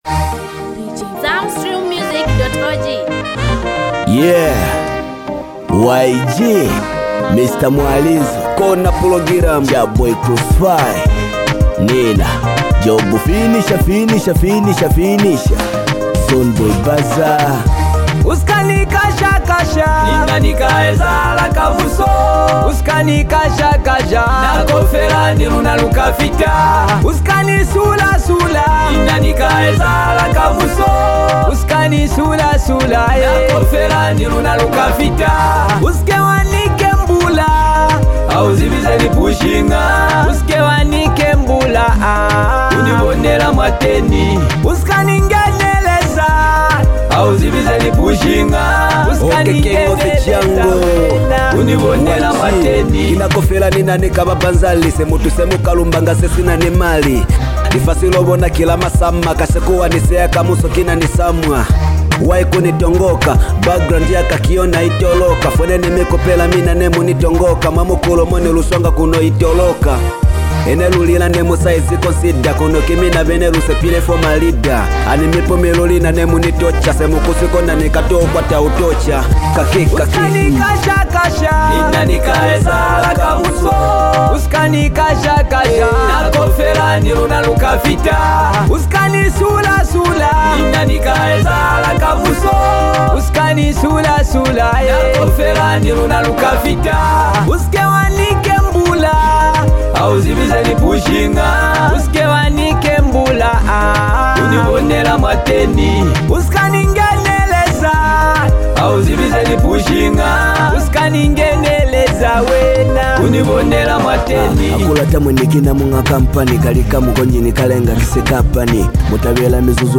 Genre: Afro-beats, Zambia Songs